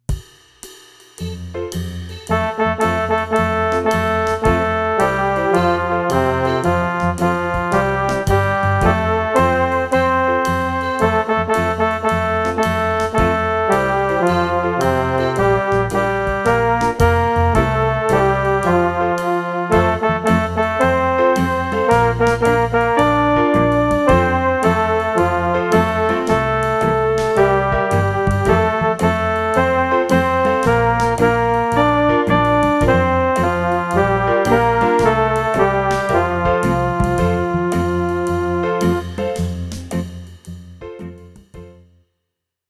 Historia del compositor: William B. Bradbury Música: MIDI